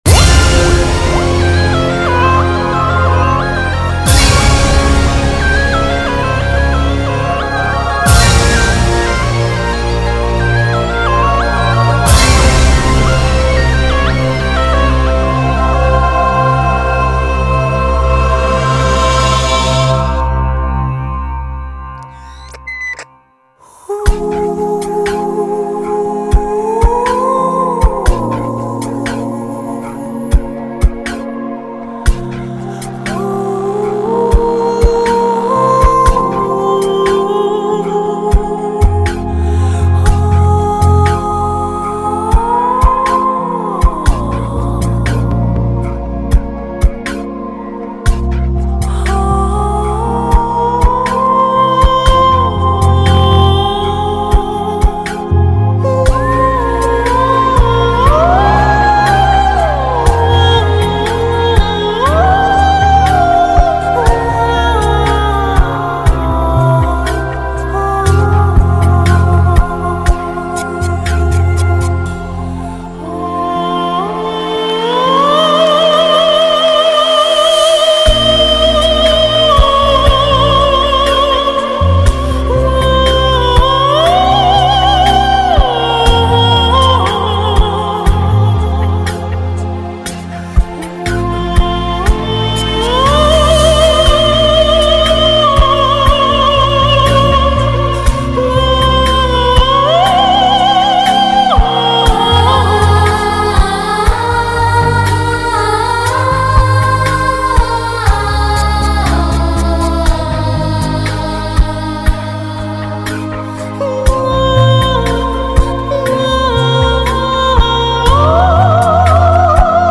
带着华丽的高音